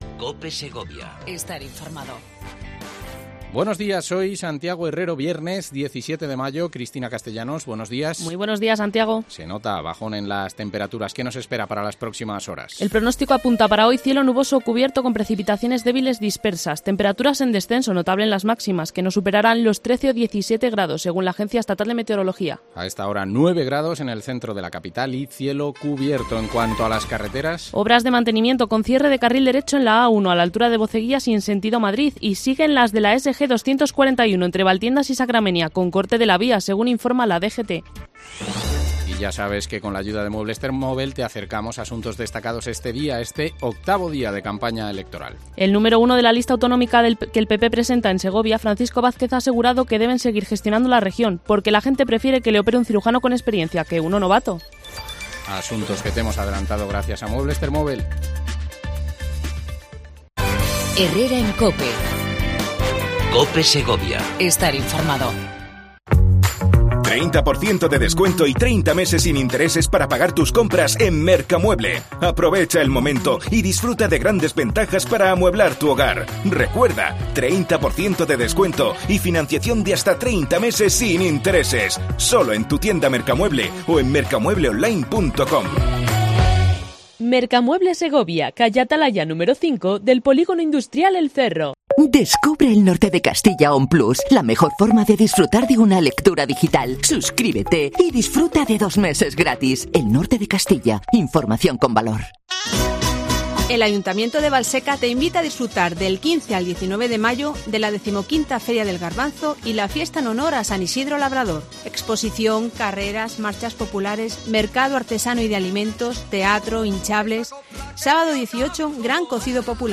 Entrevista a Alfonso Gil, Alcalde de Valseca. XV Feria del Garbanzo de Valseca (Marca de Garantía) hasta el 19 de Mayo ¡No te lo pierdas!